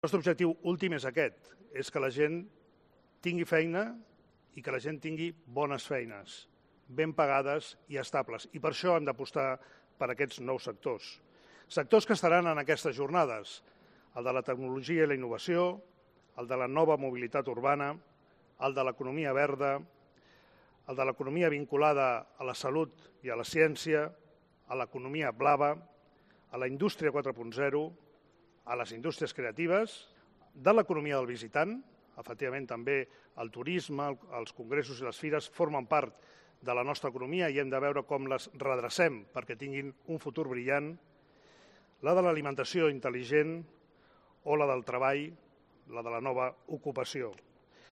Jaume Collboni, primer teniente de alcalde en Barcelona